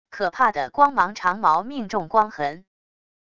可怕的光芒长矛命中光痕wav音频